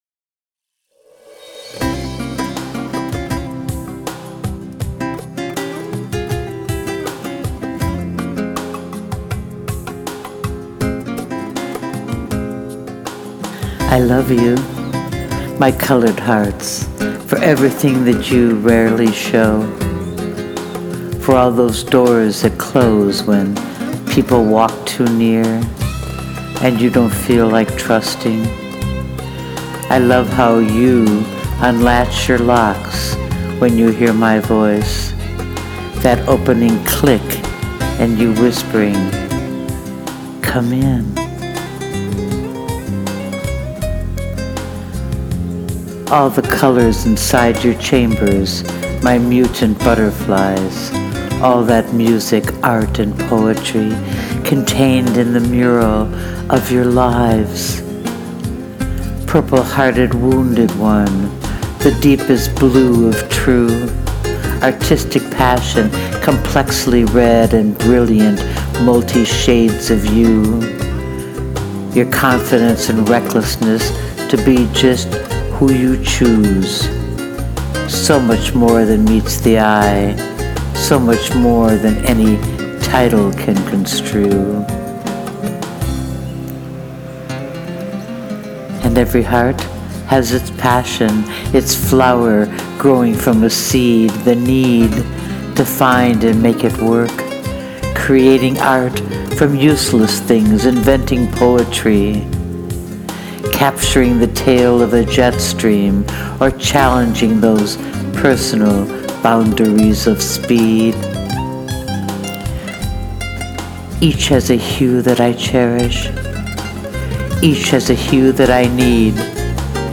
To the music “Cien Fuegos” by Johannes Linstead, my reading.
I love it, and it creates such a silky feel to this piece.